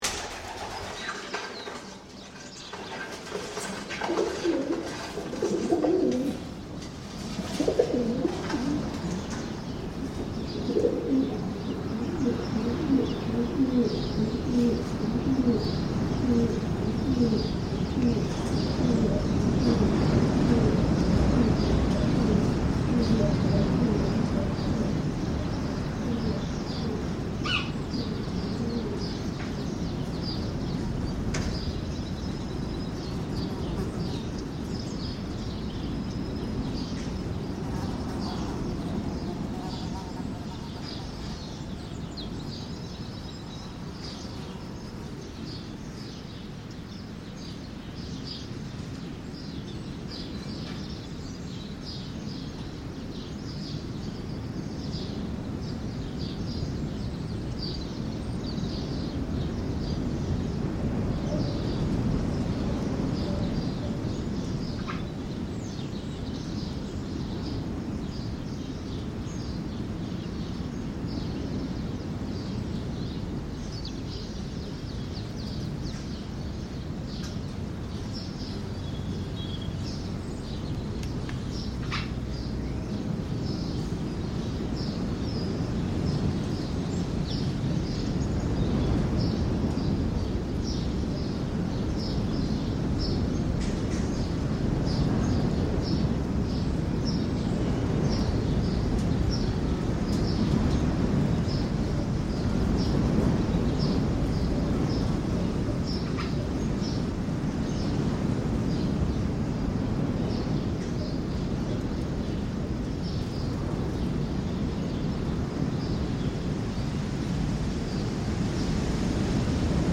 This recording is taken in one of the chambers just below the main parabolic dish, where I found many birds nesting. The microphones are inside the chamber, and in this recording we hear the calls of the birds inside the chamber close to the microphones, as well as those that are perched on the external structure of the communications station, and those in flight around it. The strong winds of the day of the recording are also heard, which whistle through the crevices of the metal work into the recording site and at times rattle the structure.